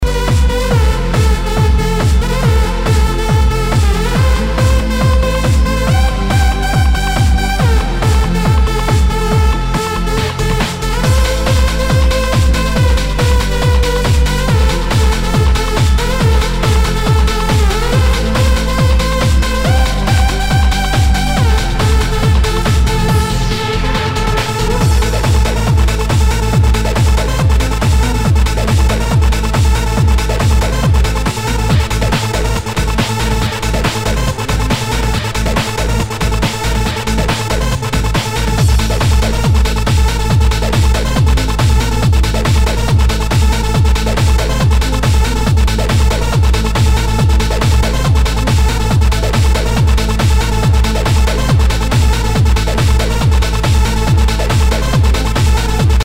HOUSE/TECHNO/ELECTRO
ナイス！ハード・トランス！